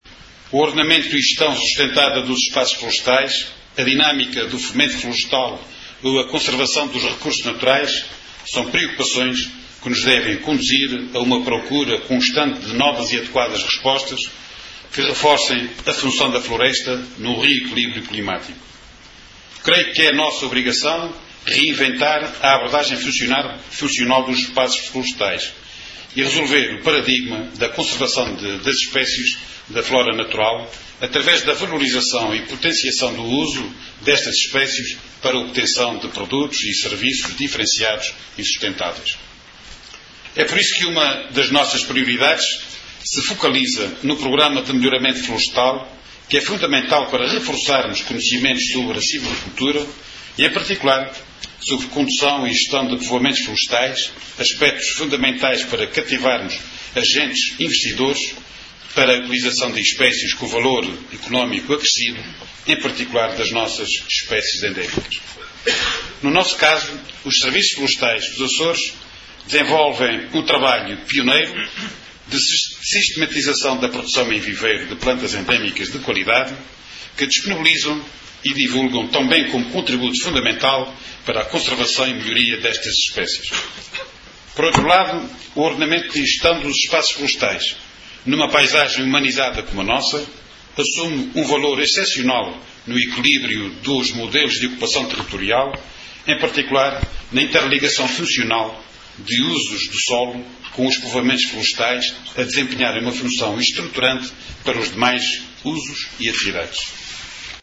Declarações de Noé Rodrigues na sessão de abertura das VI Jornadas Florestais Insulares, na qual revelou que uma das prioridades do Governo dos Açores se focaliza no programa de melhoramento florestal, que é fundamental para reforçar conhecimentos sobre silvicultura e, em particular, sobre condução e gestão de povoamentos florestais, aspetos fundamentais para cativar agentes investidores para a utilização de espécies com valor económico acrescido, em particular das plantas endémicas dos Açores.